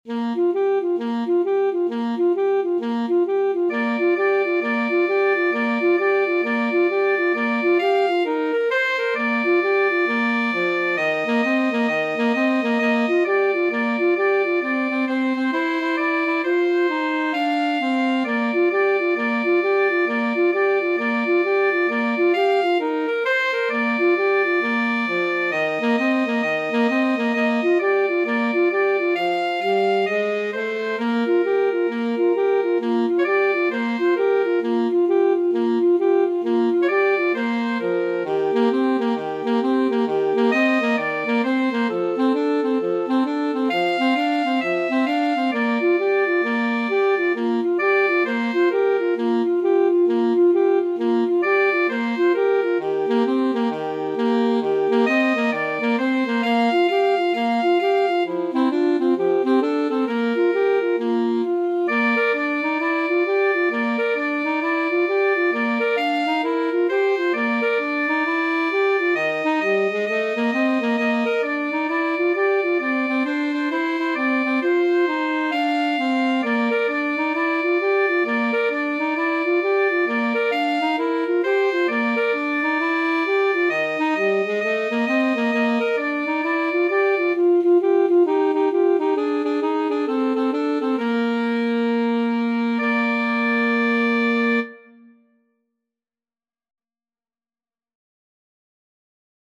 4/4 (View more 4/4 Music)
With a swing =132
Pop (View more Pop Alto Saxophone Duet Music)